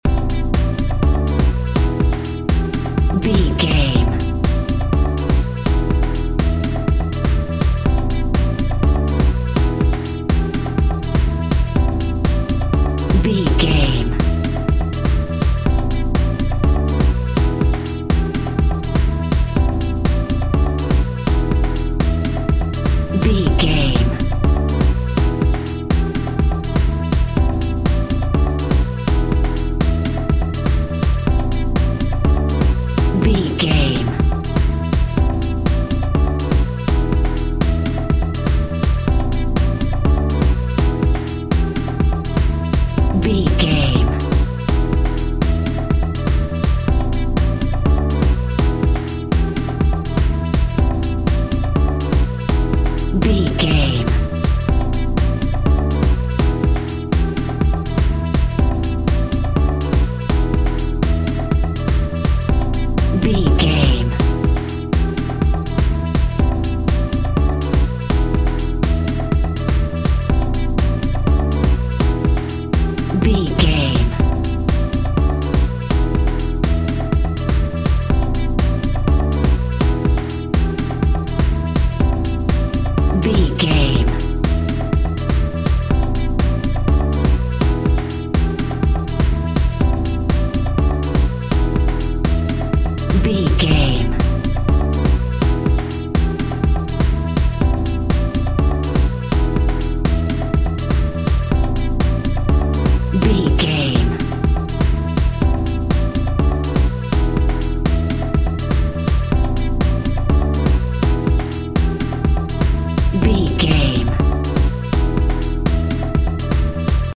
Summer Dance Music.
Aeolian/Minor
groovy
smooth
futuristic
uplifting
house
electro house
synth drums
synth leads
synth bass